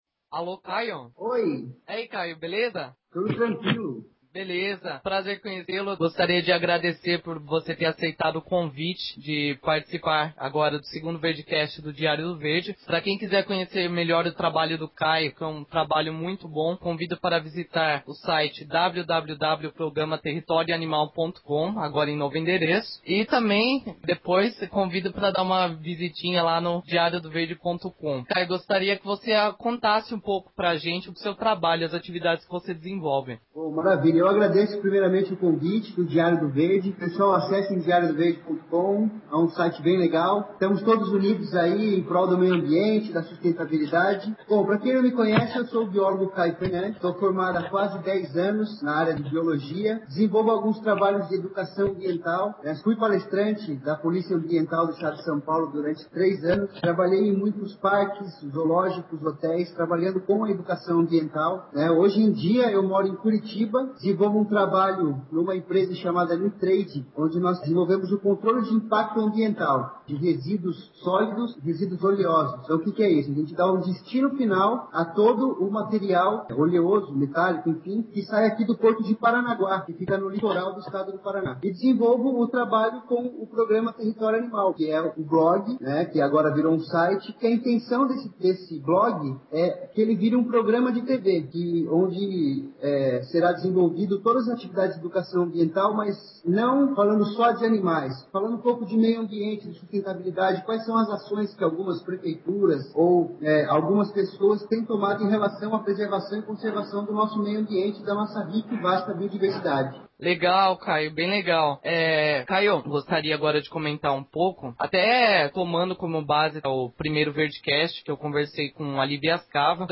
PS: O áudio está um tanto com ruídos, apesar da edição.